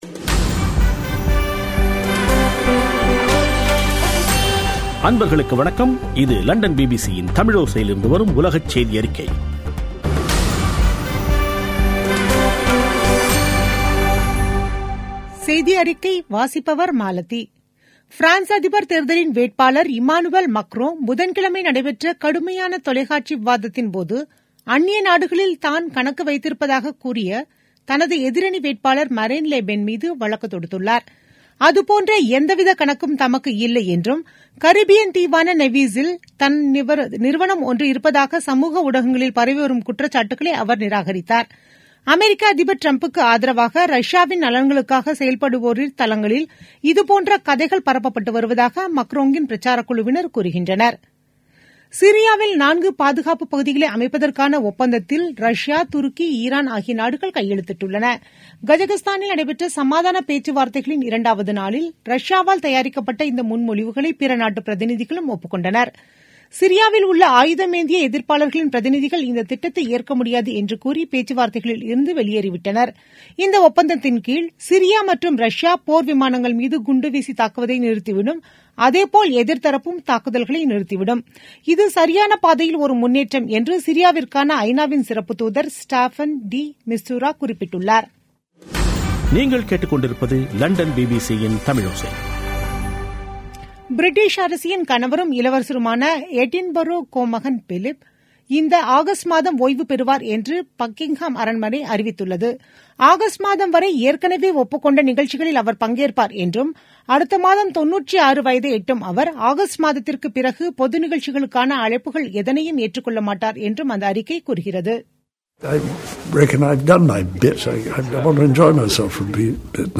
பிபிசி தமிழோசை செய்தியறிக்கை (04/05/2017)